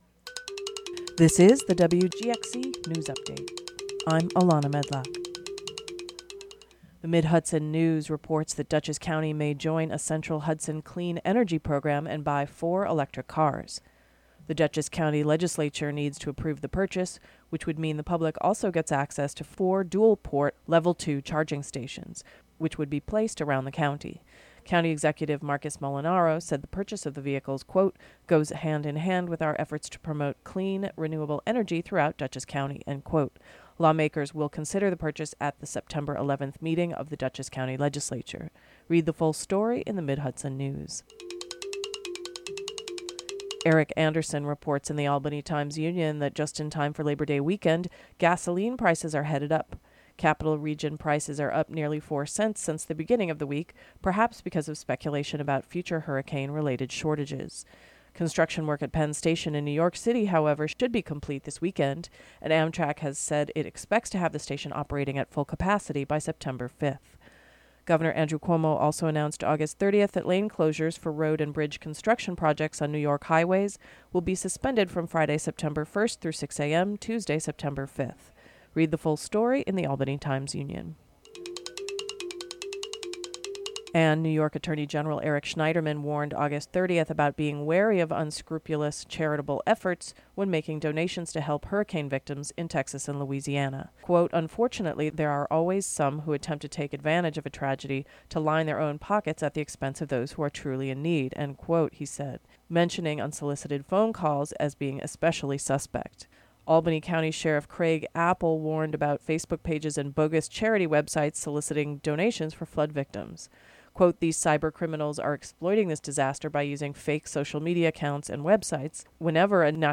DOWNLOAD or play the audio version of the local news update for Thursday, August 31 (3:07).